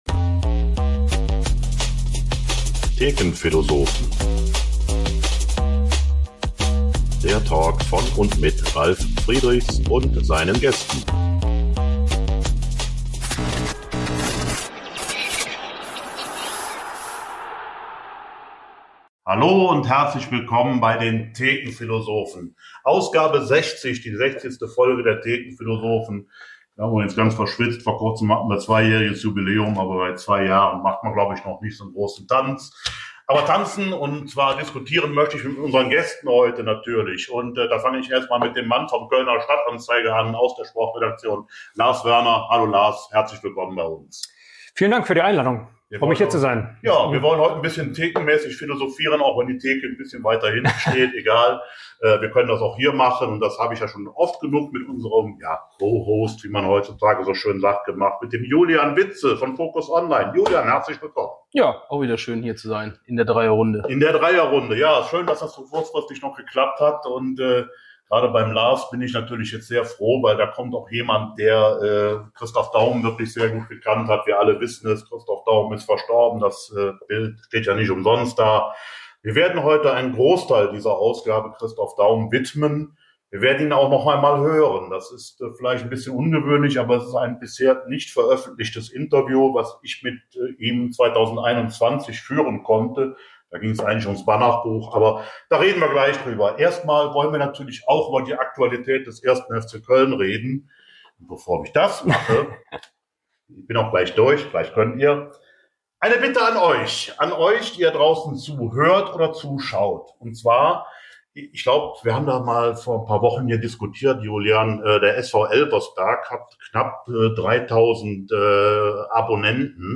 Besonderes Highlight: Neun Minuten eines bisher unveröffentlichten Telefon-Interviews mit Daum, in dem er unter anderem den sportlichen Niedergang des FC in den 90er Jahren erklärt, werden präsentiert und analysiert.